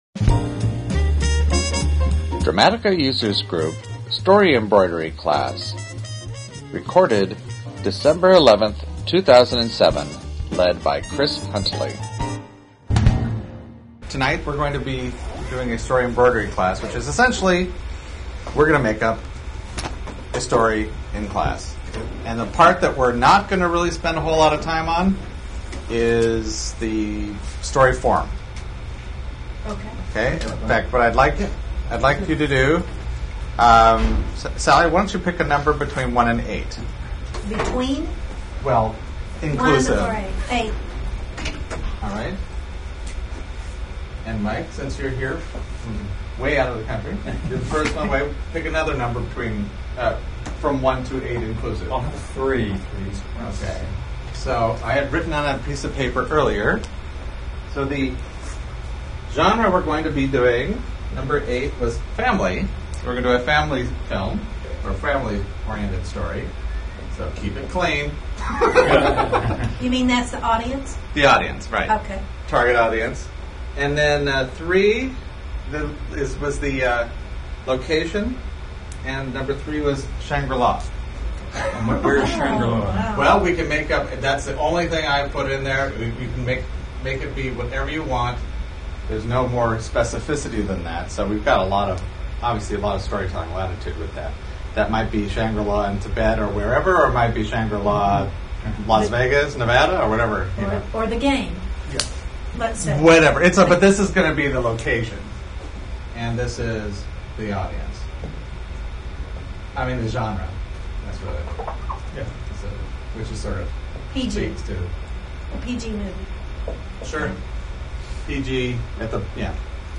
Delightful recordings of Dramatica Users attempting to better understand what makes great stories so great. Each podcast focuses on a popular or critically-acclaimed film. By breaking down story into fine detail, the group gains a better appreciation of the theory and how better to apply the concepts into their own work.